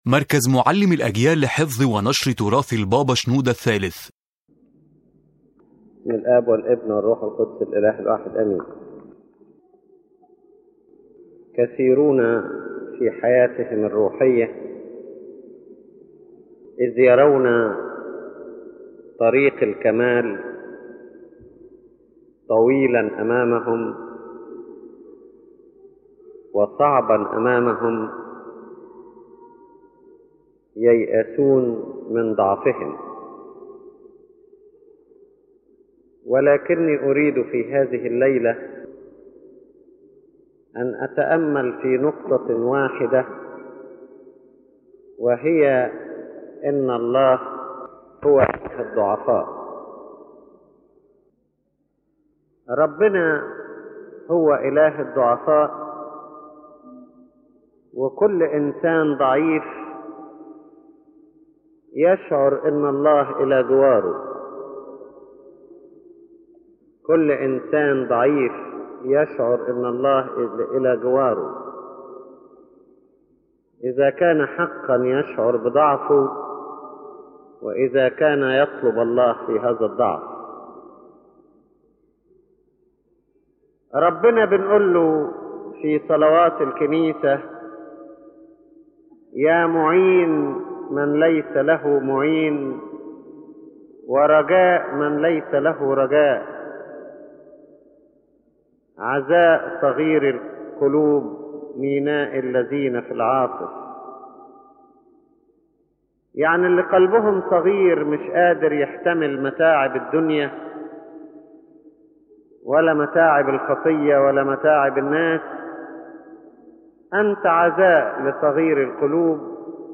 His Holiness Pope Shenouda speaks about a fundamental spiritual truth: that God is the God of the weak and the poor, and this is His constant way of dealing with people.